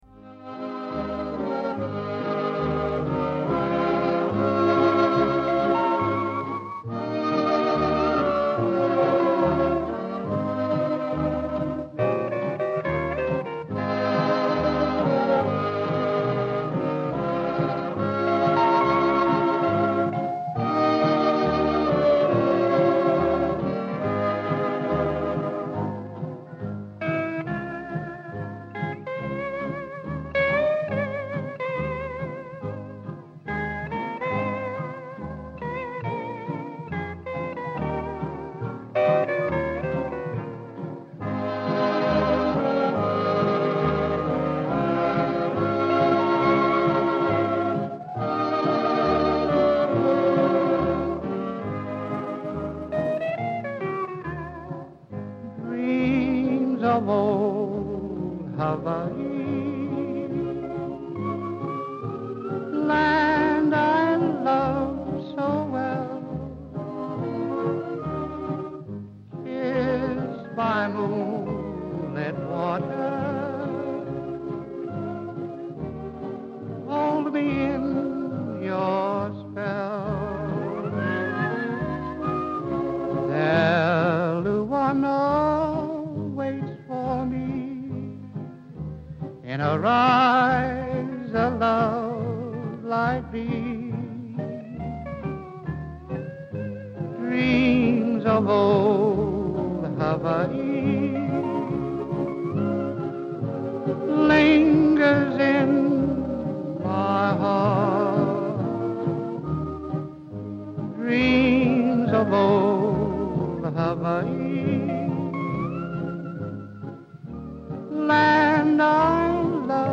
μουσικές της Έβδομης Τέχνης